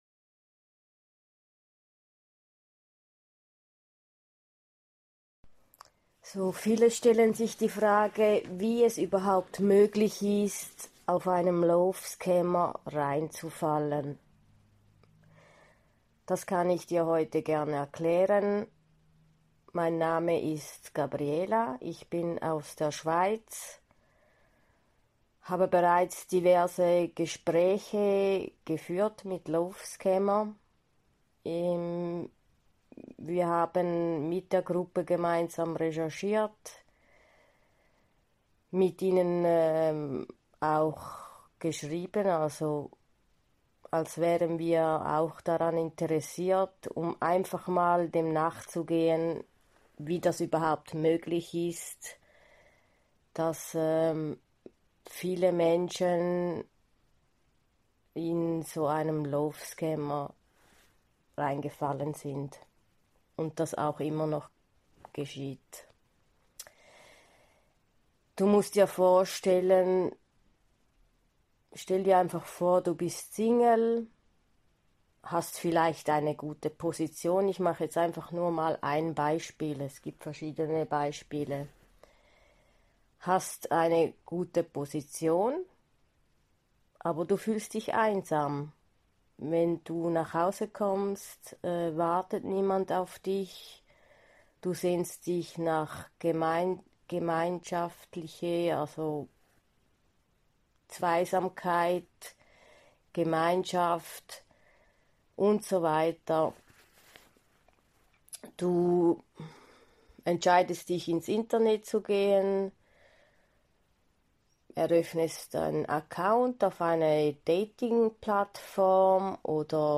In meinem Podcast erkläre ich, wie es möglich ist, in einen solchen Betrüger hereinzufallen. Ich habe mich einige Male verzettelt und wiederholt, aber das ist Teil meiner Persönlichkeit und Redegeschicklichkeit, diese wird sich bestimmt vorzu verbessern.